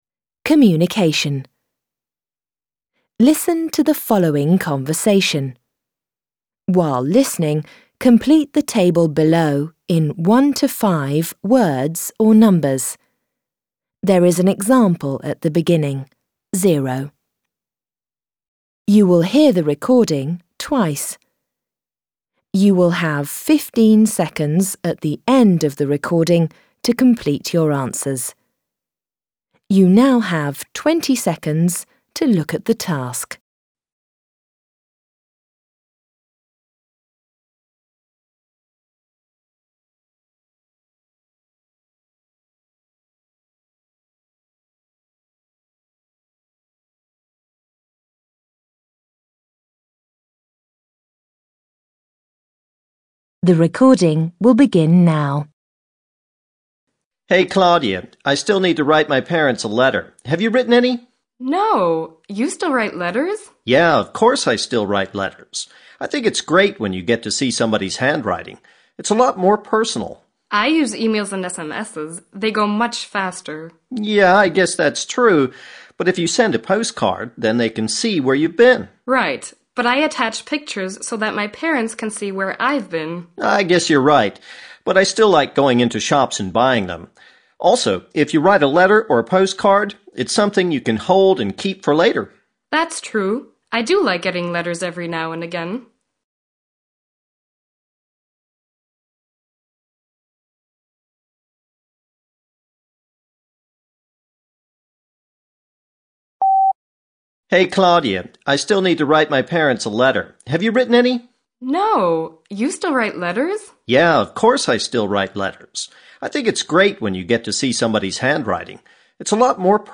Alltagsgespräch